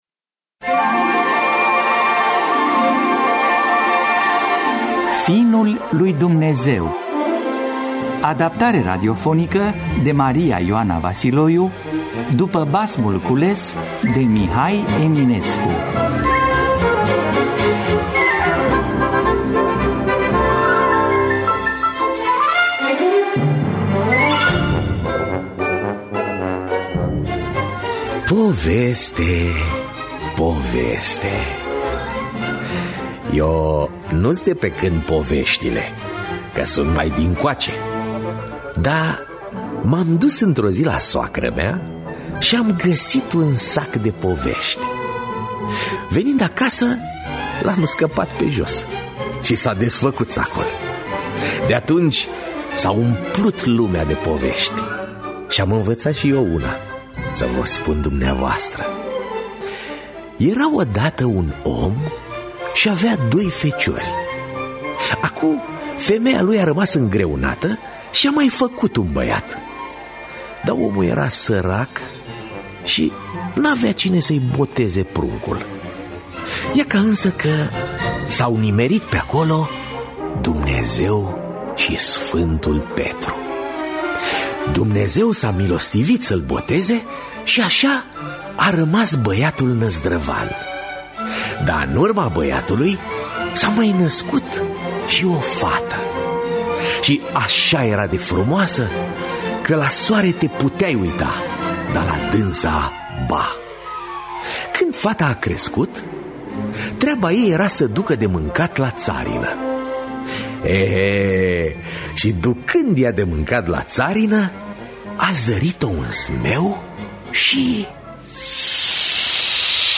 Teatru Radiofonic Online
Adaptarea radiofonică